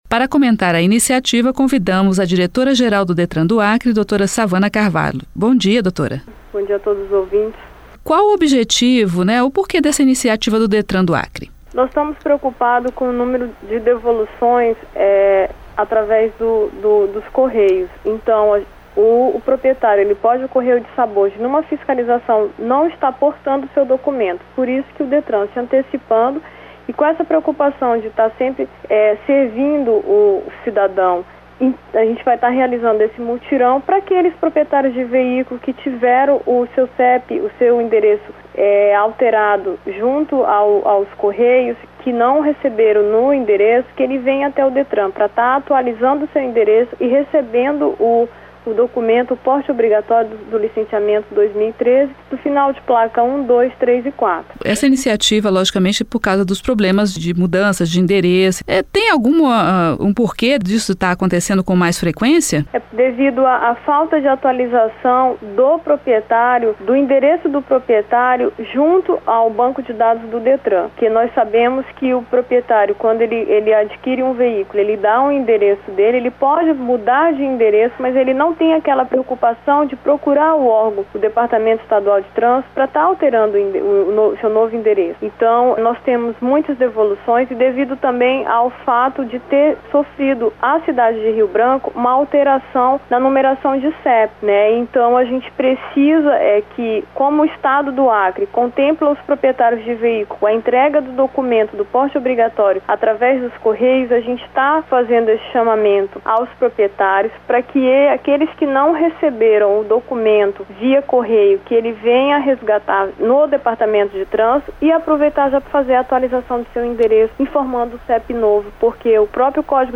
Diretora geral do Detran-AC fala sobre multirão para entrega de documentos Entrevista com Sawana Carvalho, diretora do Detran do Acre.